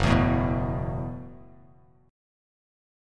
Gbm11 Chord
Listen to Gbm11 strummed